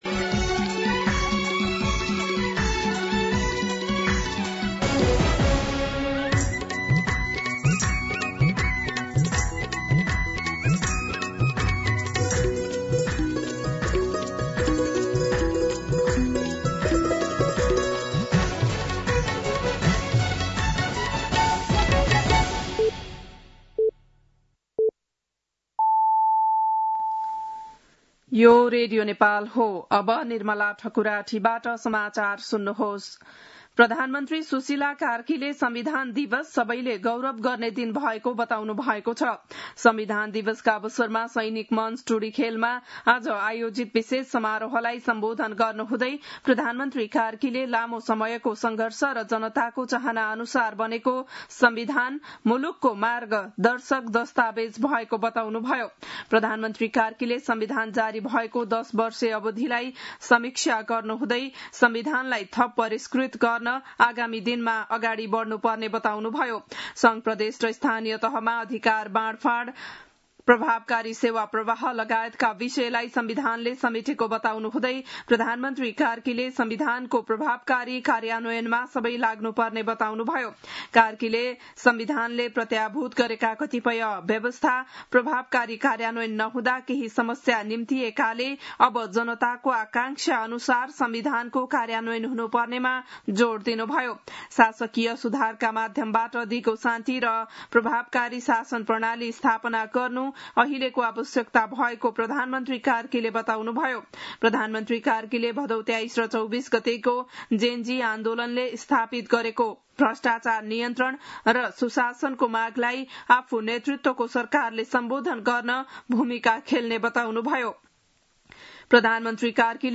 बिहान ११ बजेको नेपाली समाचार : ३ असोज , २०८२
11-am-Nepali-News-1.mp3